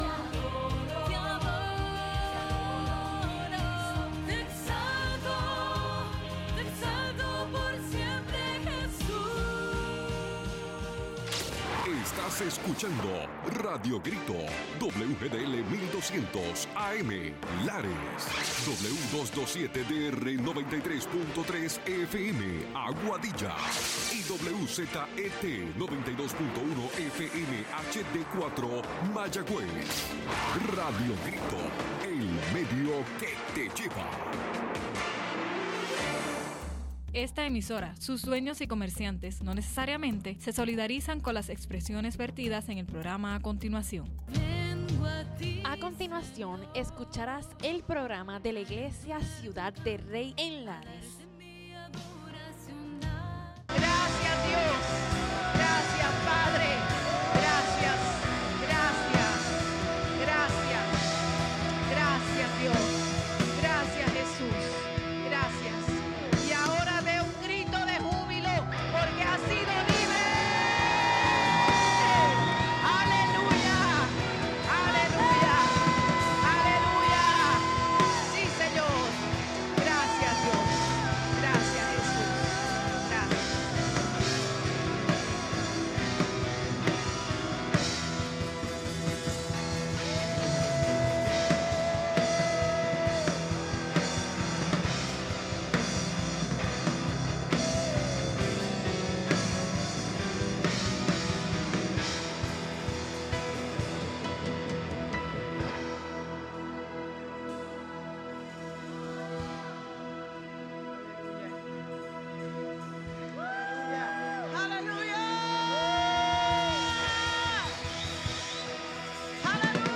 Los hermanos de Ciudad del Rey nos traen un programa especial de su servicio en la iglesia